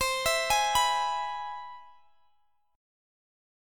Listen to C+M7 strummed